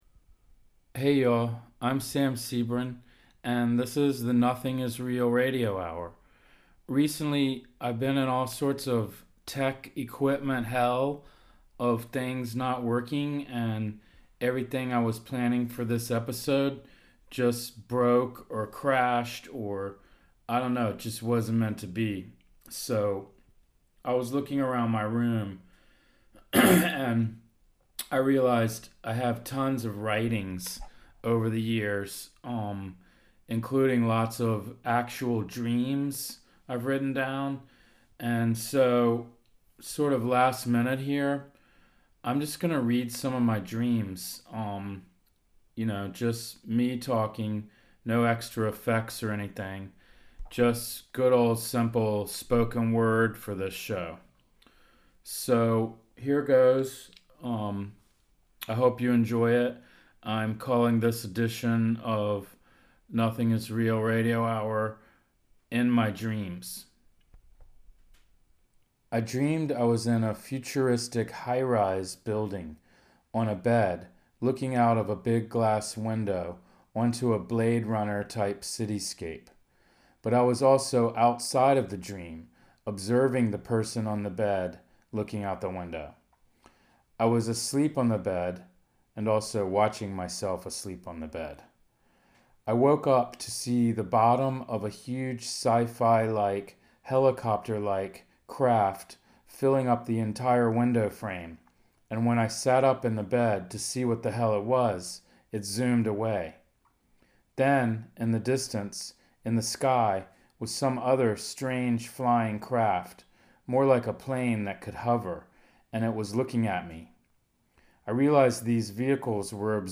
reads selections